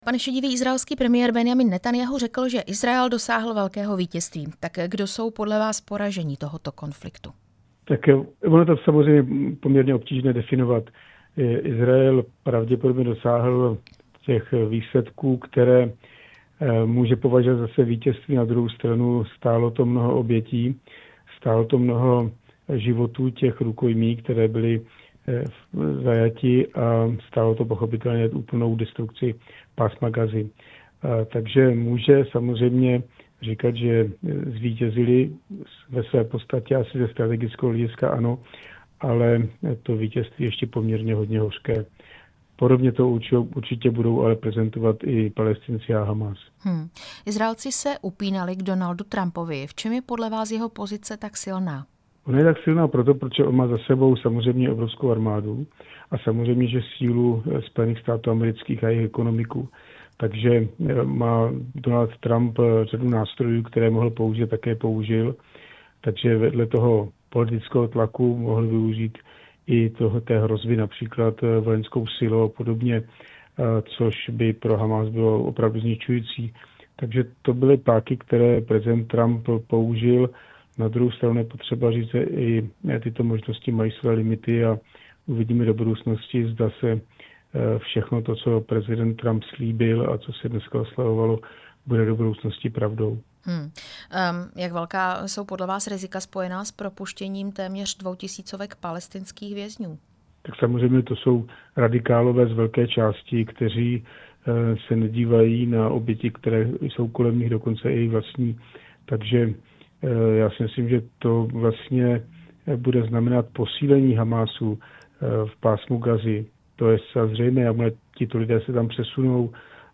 Rozhovor s bývalým náčelníkem generálního štábu Jiřím Šedivým